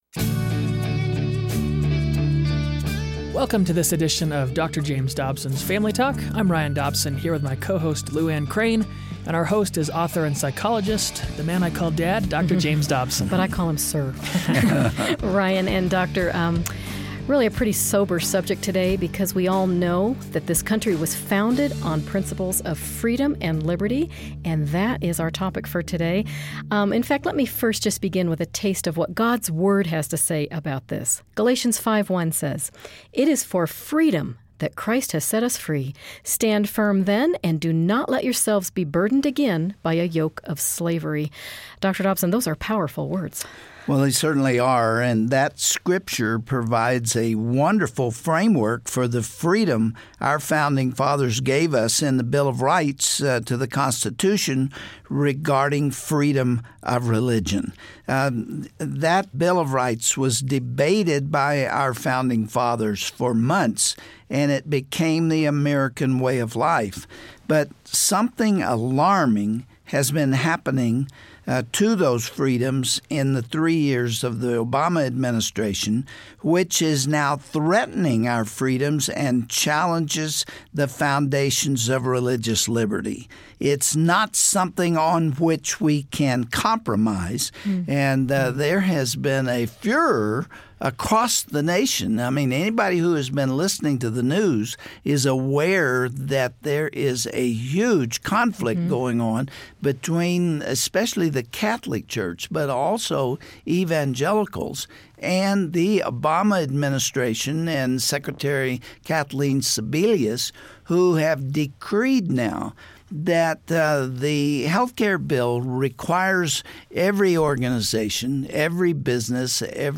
Our religious liberties are under attack! Today, Dr. James Dobson is joined by three brave warriors who are on the front lines of this battle to protect our freedoms.